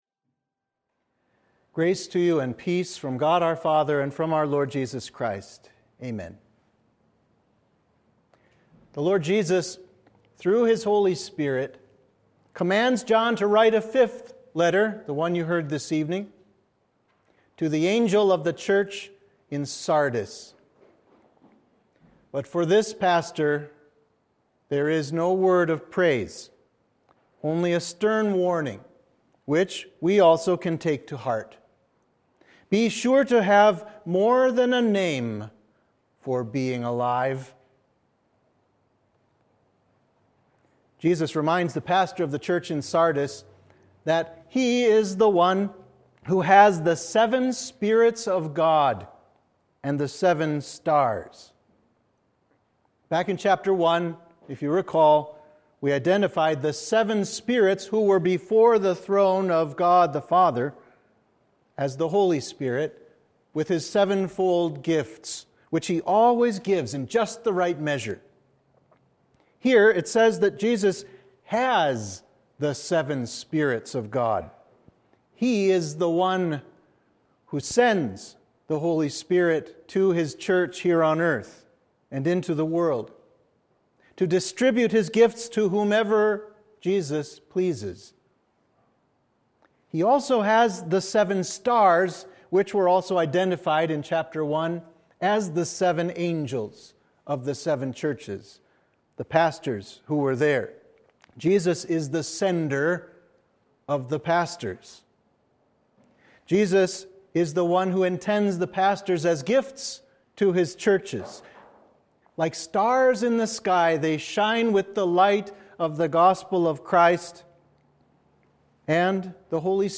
Sermon for Midweek of Oculi